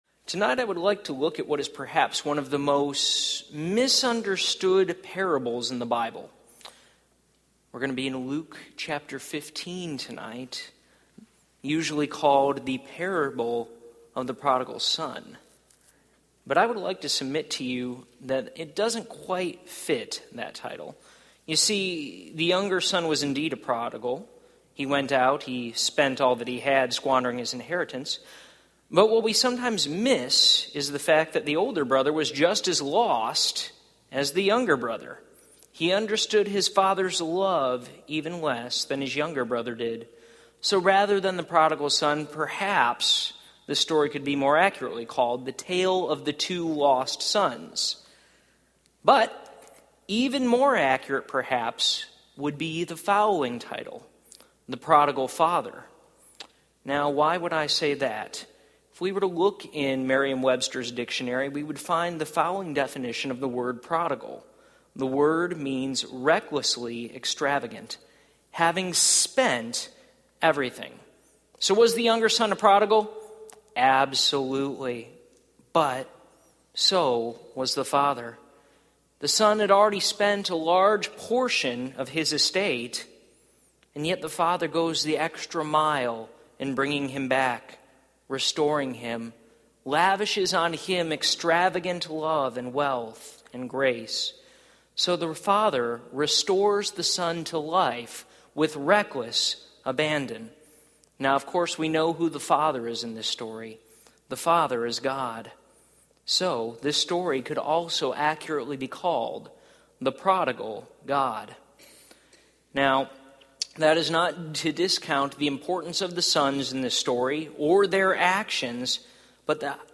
Topical Service Type: Sunday Evening « Counterfeit Gods